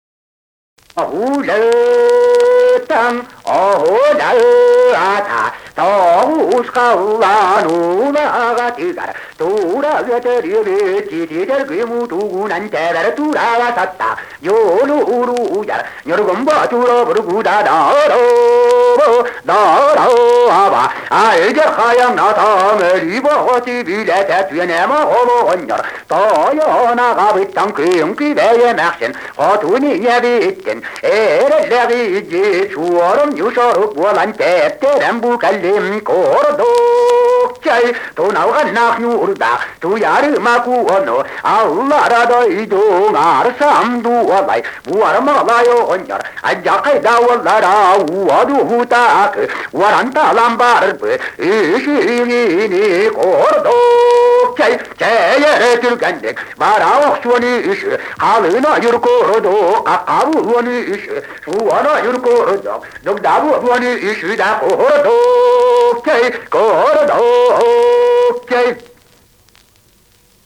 Якутский героический эпос "Кыыс Дэбилийэ"
Песня посыльного Сорук Боллура